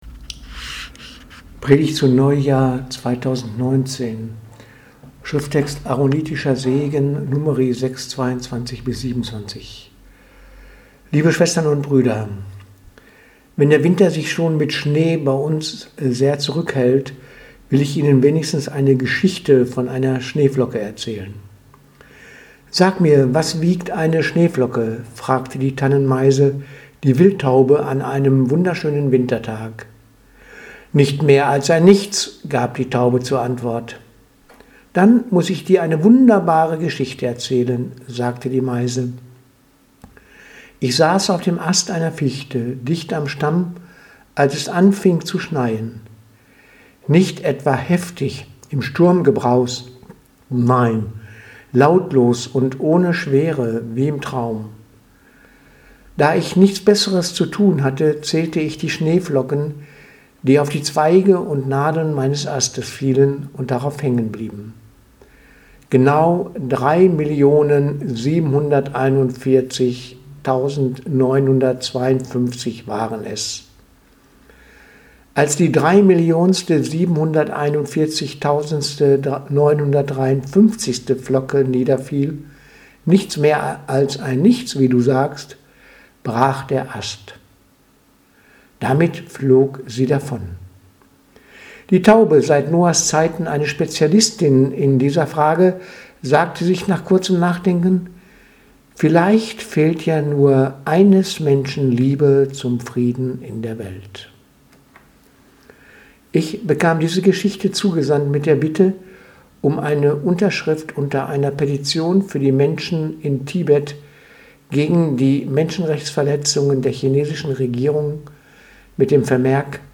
Predigt vom 9.1.2019 -Neujahr